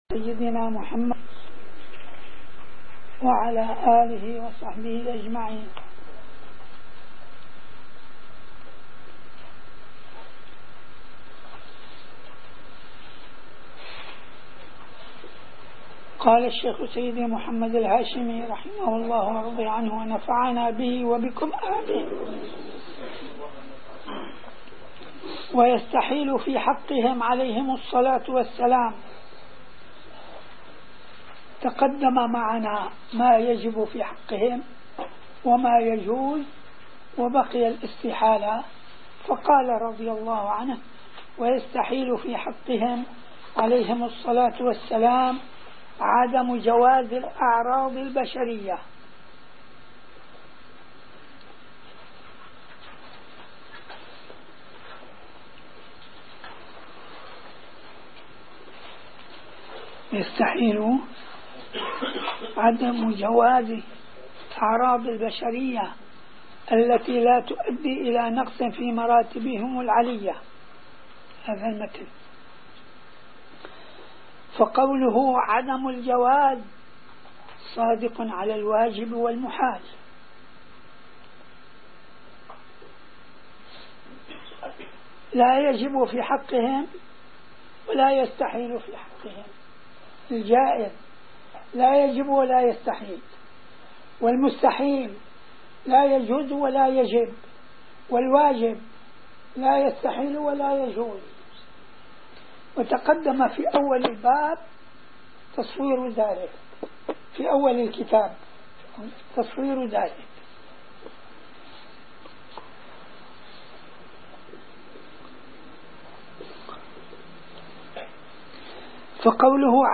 - الدروس العلمية - شرح كتاب مفتاح الجنة والوظيفة الشاذلية - الدرس الثاني والعشرون: من الصفحة 213 إلى الصفحة 225